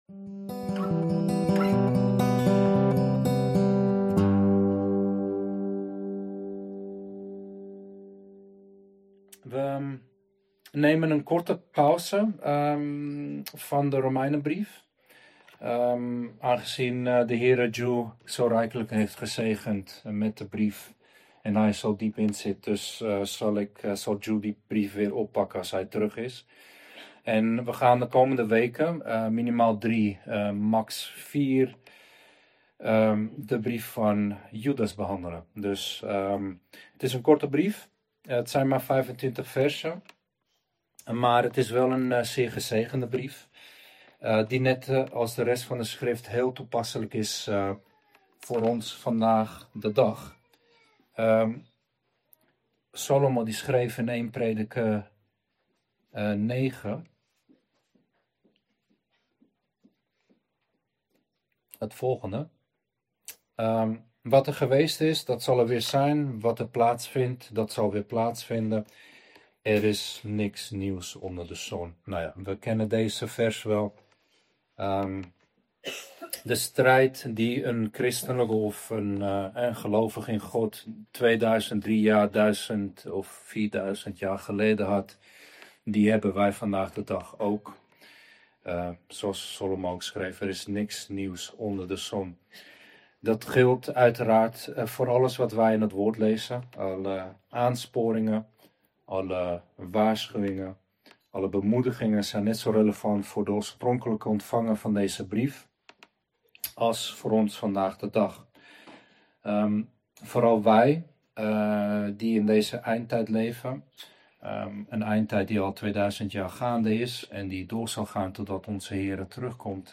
Preek over Judas 1:1-2 | Bijbeluitleg